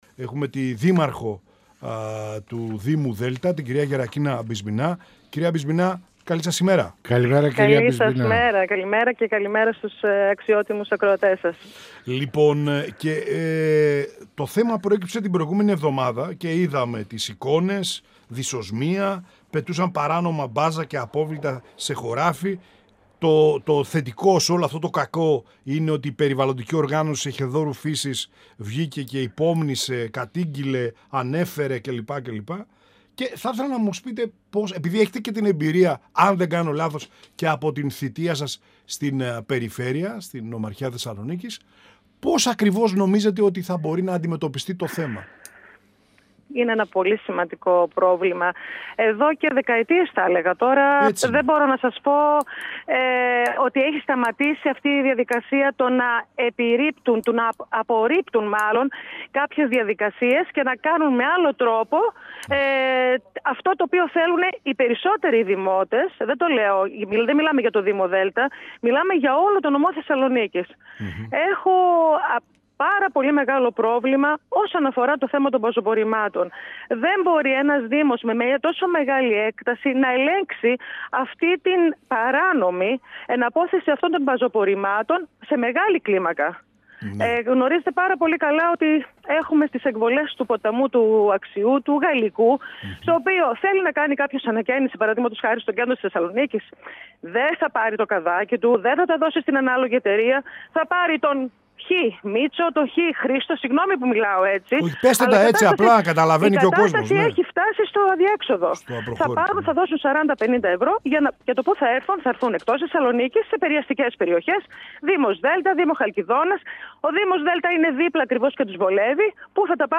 Στα κρούσματα παράνομης απόρριψης σκουπιδιών και μπάζων σε αυτοσχέδιες χωματερές στην περιοχή της Σίνδου του Δήμου Δέλτα και τα απολύτως αναγκαία-επείγοντα μέτρα προστασίας της Δημόσιας Υγείας και του Περιβάλλοντος αναφέρθηκε η Δήμαρχος Δέλτα Γερακίνα Μπισμπινά, μιλώντας στην εκπομπή «Πανόραμα Επικαιρότητας»  του 102FM της ΕΡΤ3.